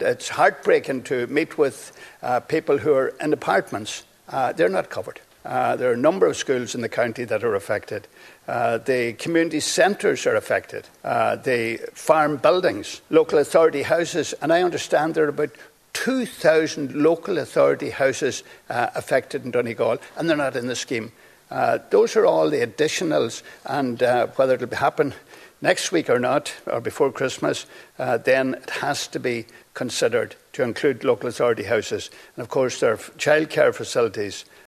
The second stage of the bill introducing amendments to Defective Concrete Block redress legislation were carried out in the Dáil chamber yesterday evening, with Donegal TDs having their voices heard.